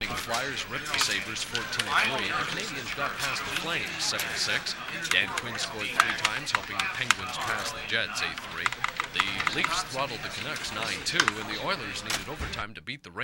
Announcers
TV Announcer Sports with Futz And Background